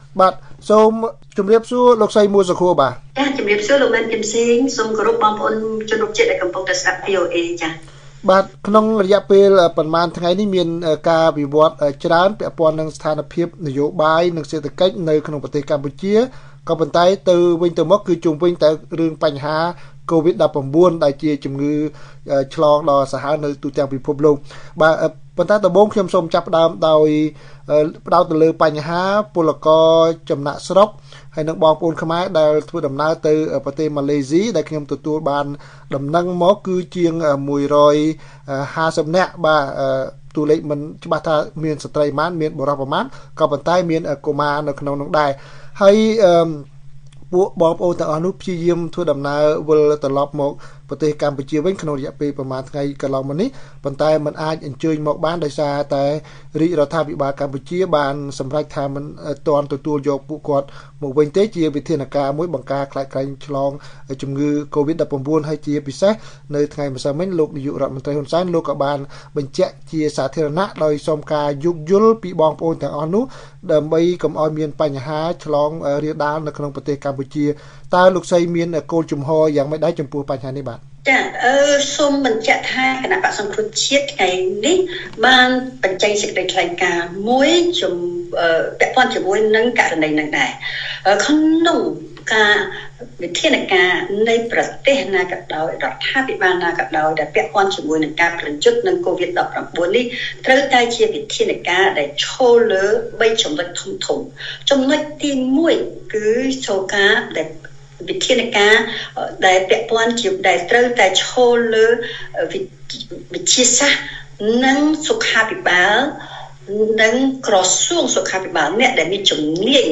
បទសម្ភាស VOA៖ បក្សសង្គ្រោះជាតិស្នើលោកហ៊ុន សែន ទទួលយកពលករខ្មែរពីម៉ាឡេស៊ី